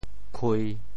khui3.mp3